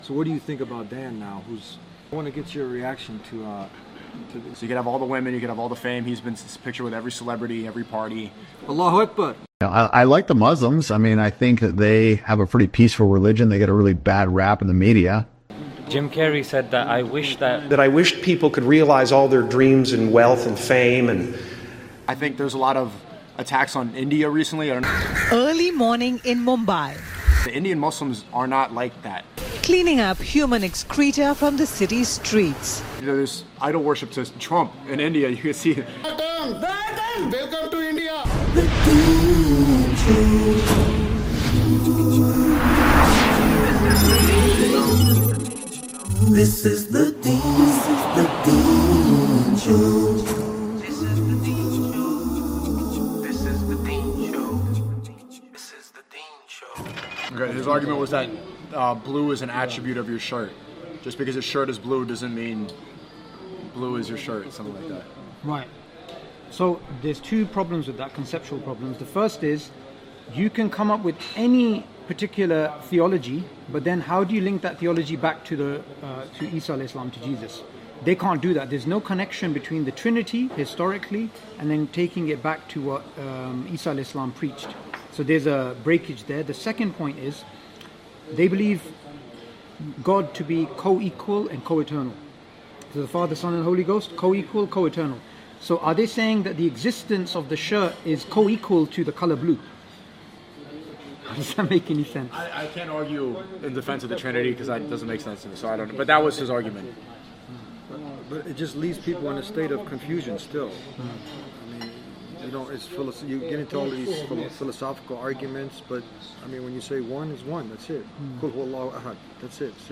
An Unexpected Discussion with Sneako on Dan Bilzerian, Hedonism, Trump Worship, JESUS & The TRINITY